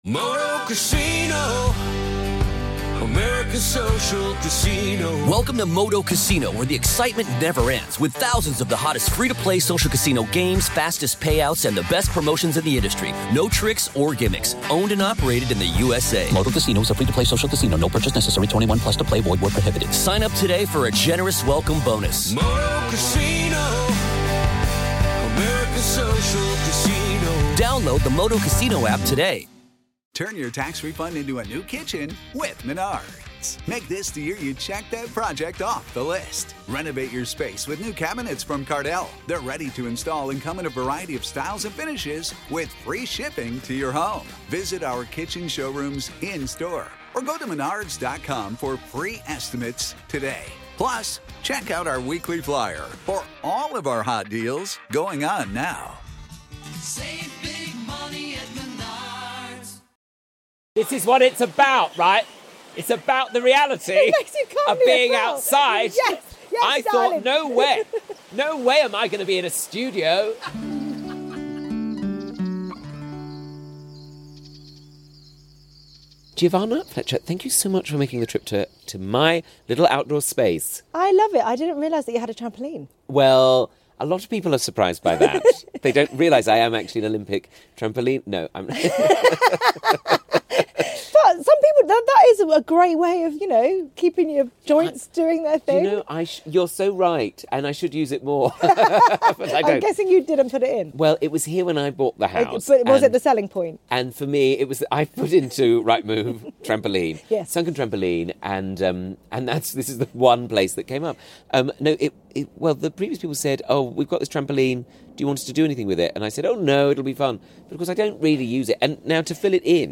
Oh it was truly a delight to have the wonderful Giovanna Fletcher join me in the garden. Actress, author, podcaster and gardening enthusiast, Giovanna is an absolute joy and it was a real pleasure chatting about mulch, motherhood, and most things in between.